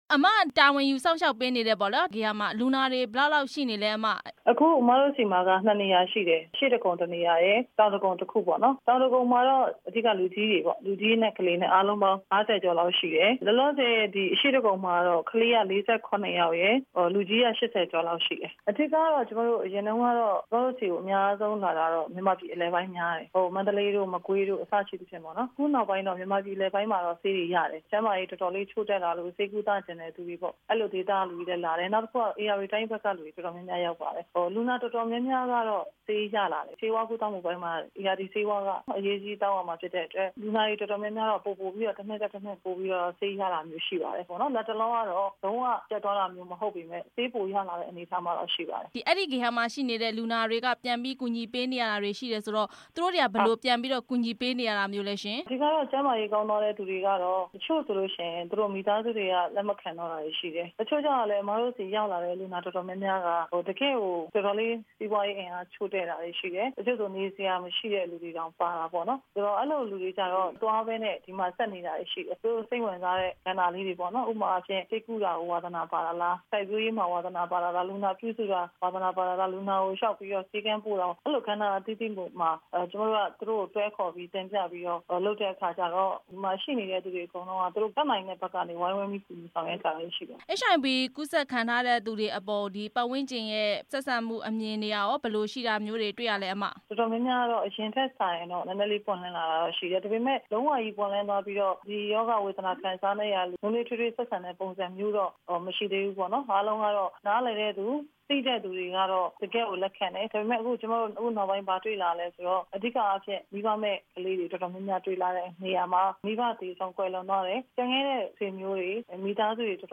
ပြည်သူ့လွှတ်တော် ကိုယ်စားလှယ် ဒေါ်ဖြူဖြူသင်းနဲ့ မေးမြန်းချက်